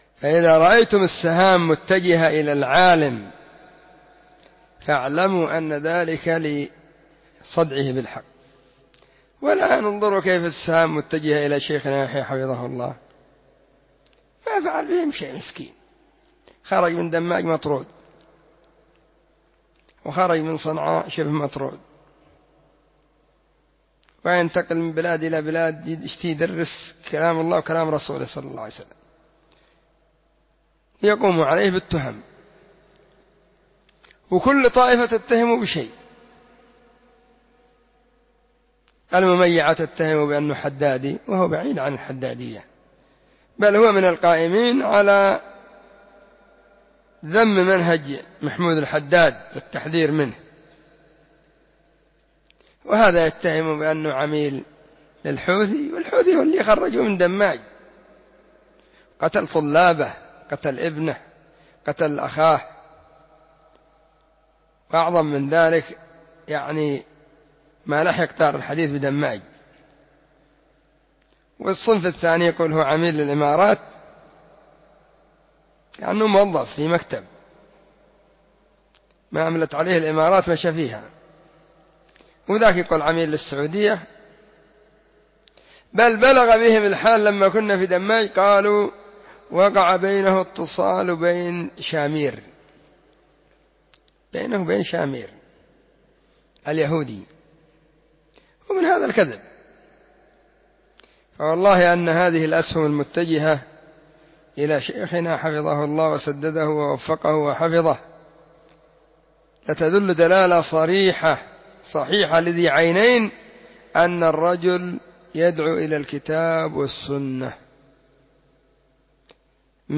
الجمعة 15 ذو القعدة 1442 هــــ | كلمــــات | شارك بتعليقك